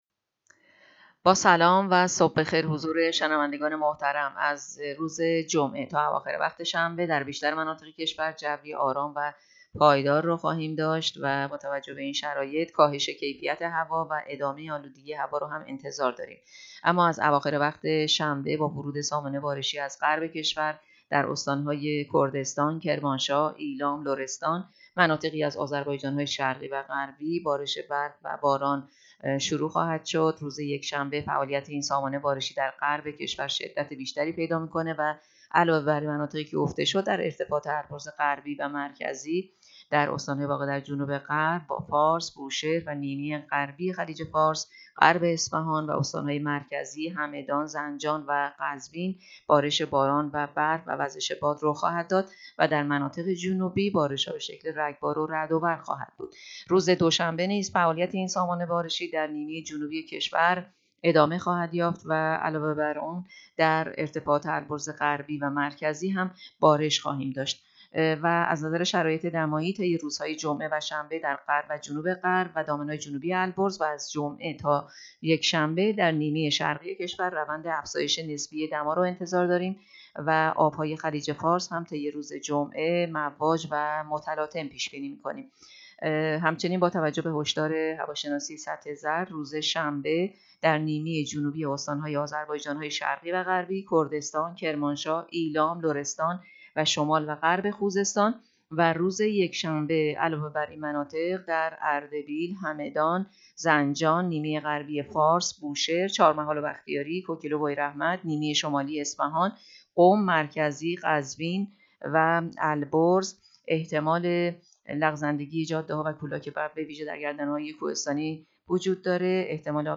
گزارش رادیو اینترنتی پایگاه‌ خبری از آخرین وضعیت آب‌وهوای ۲۰ دی؛